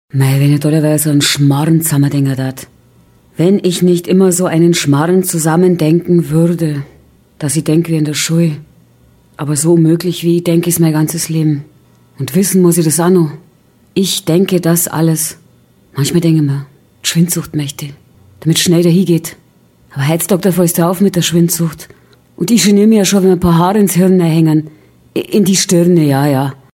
Werbung Mix